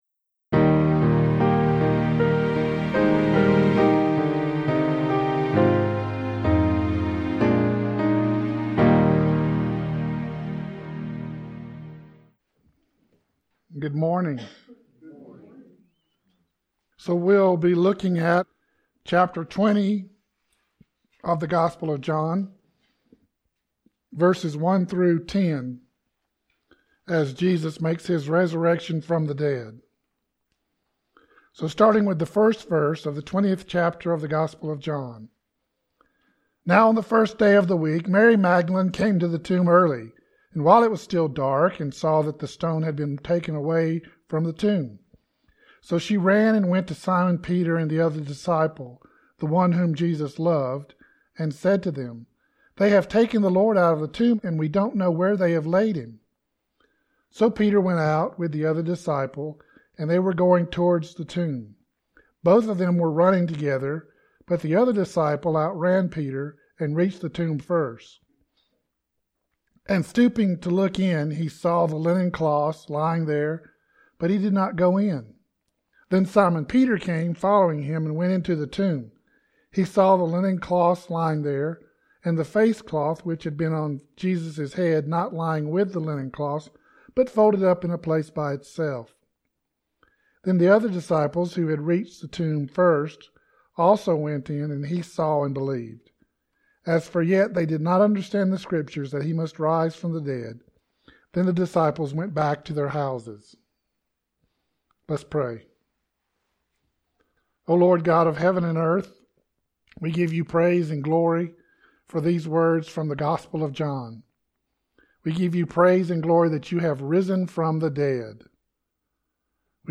Sermon - He Is Risen